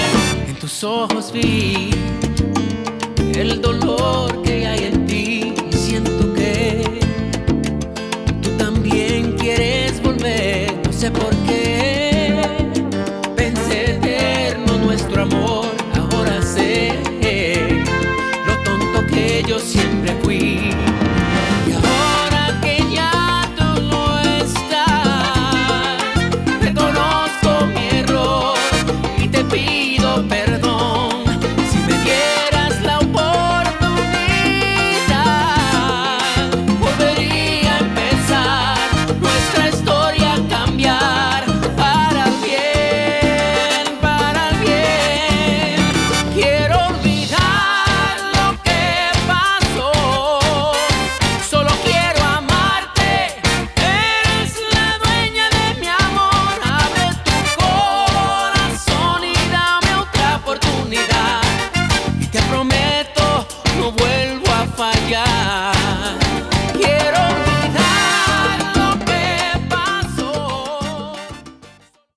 LATIN TROPICAL SOUL EXPERIENCE
is heartfelt, romantic pop Salsa track.